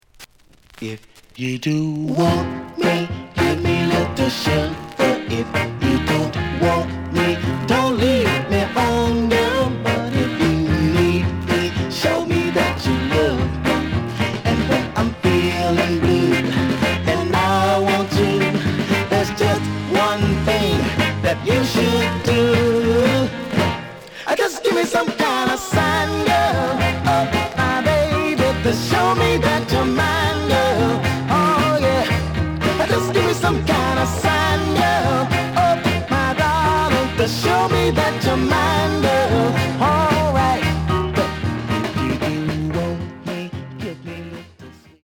The audio sample is recorded from the actual item.
●Genre: Soul, 60's Soul
Some noise on A side.